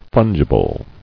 [fun·gi·ble]